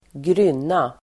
grynna substantiv, Uttal: [gryn:a] Böjningar: grynnan, grynnorDefinition: litet (undervattens)grund